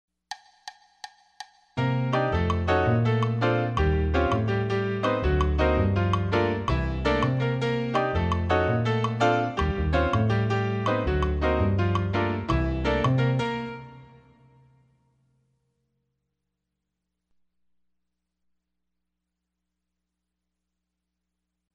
Latin Stück Midi
ich hab hier ein ähnliches montuno wie deines gefunden hör mal einmal mit der 2/3 clave und dann mit der 3/2